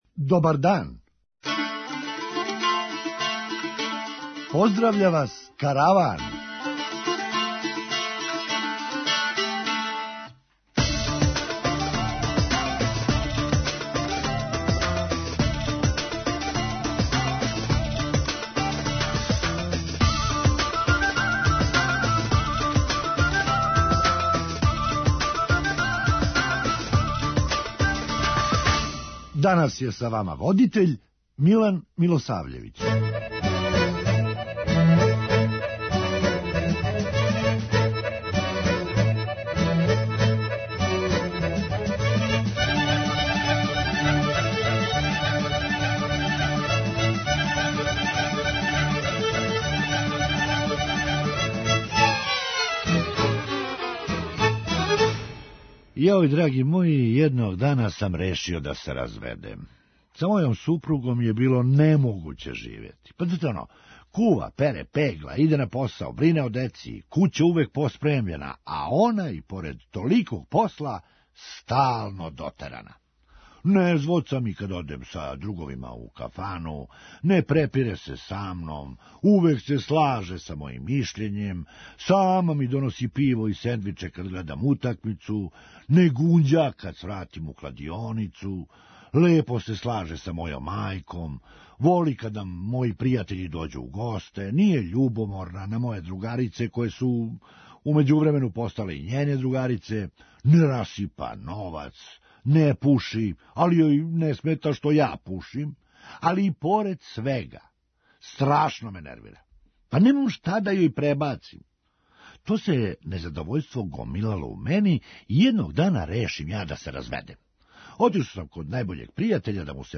Хумористичка емисија
До тог закључка дошао сам и сам: више ми прија кад пијем после подне, него кад пијем пре подне. преузми : 9.57 MB Караван Autor: Забавна редакција Радио Бeограда 1 Караван се креће ка својој дестинацији већ више од 50 година, увек добро натоварен актуелним хумором и изворним народним песмама.